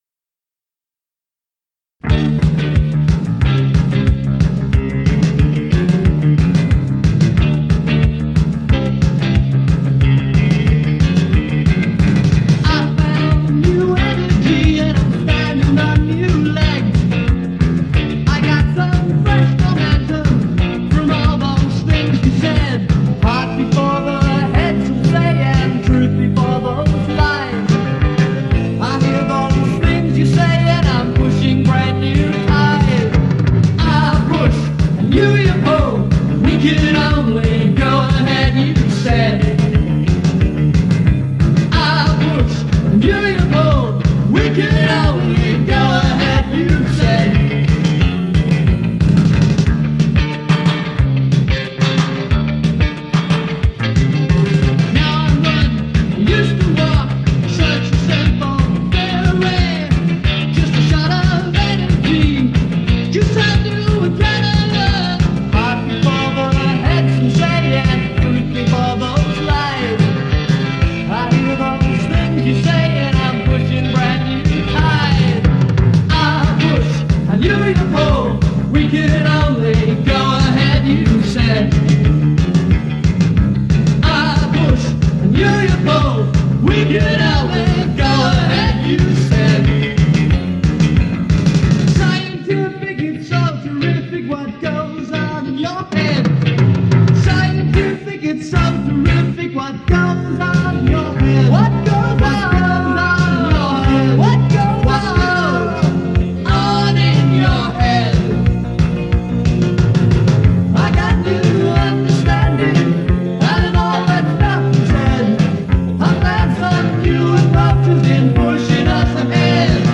lead vocals/bass/keyboards